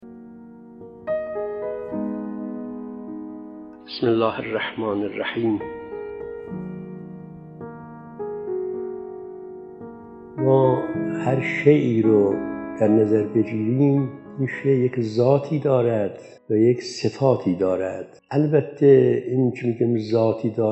📍از جلسه اولین جمعه ماه قمری| ماه رجب 🎙علم و معرفت(۱) 📌عینیت علم و نگاه معرفتی در تربیت نفس ⏳۱۱ دقیقه 🔗پیوند دریافت👇 🌐